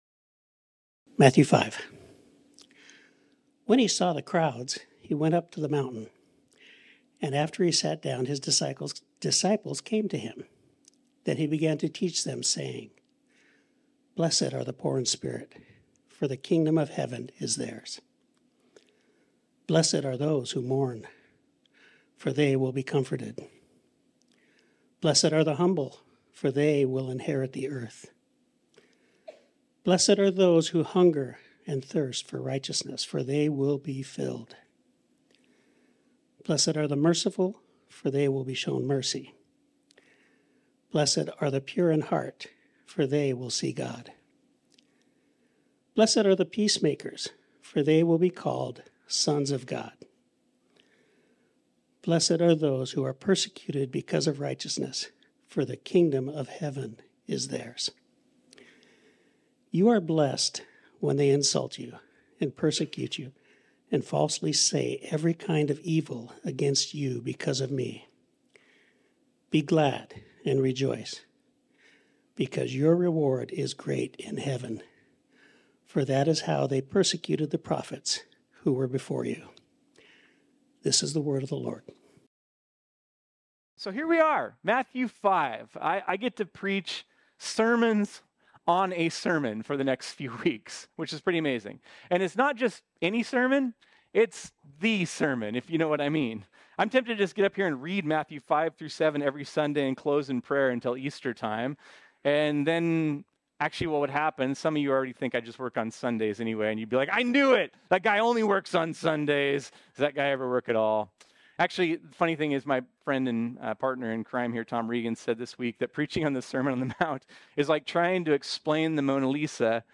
This sermon was originally preached on Sunday, January 28, 2024.